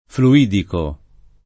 [ flu- & diko ]